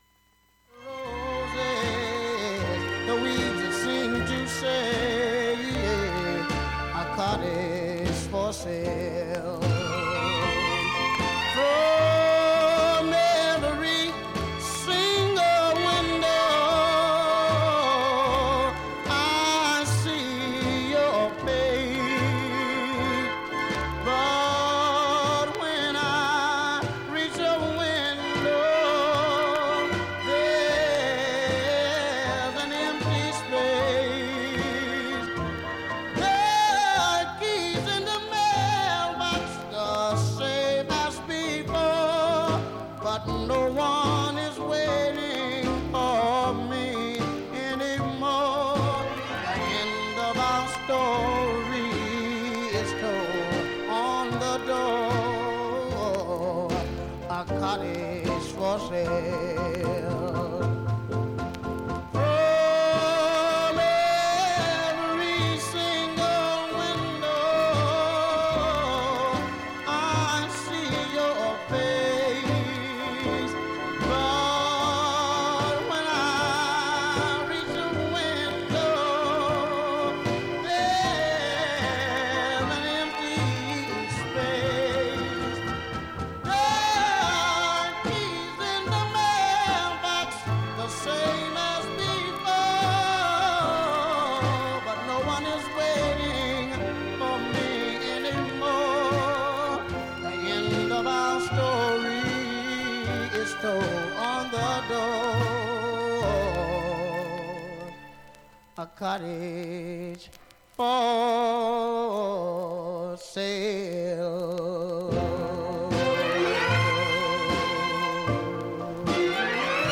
90秒の間に周回プツが出ます。
A-3後半に縦4ミリスレでかすかなプツ出ます。
現物の試聴（上記録音時間4分半）できます。音質目安にどうぞ